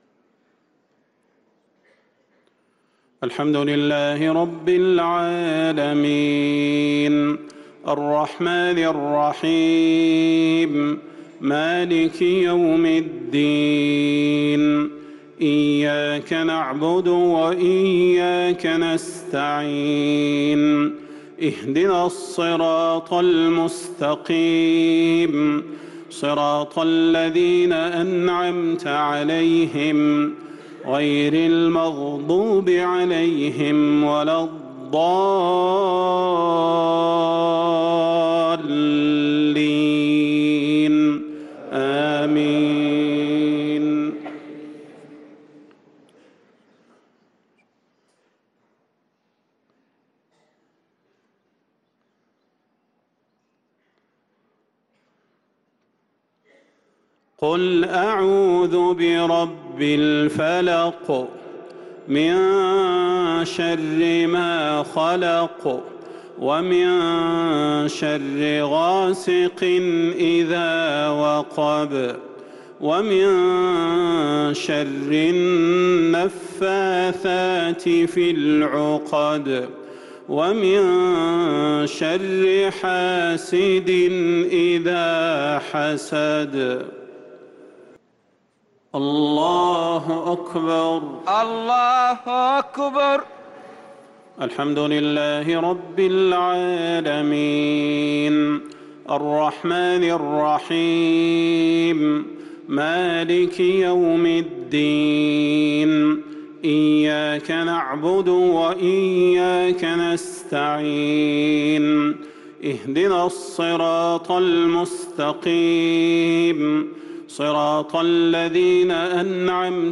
صلاة المغرب للقارئ صلاح البدير 28 ربيع الأول 1444 هـ
تِلَاوَات الْحَرَمَيْن .